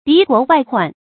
敵國外患 注音： ㄉㄧˊ ㄍㄨㄛˊ ㄨㄞˋ ㄏㄨㄢˋ 讀音讀法： 意思解釋： 指來自敵對國家的侵略騷擾。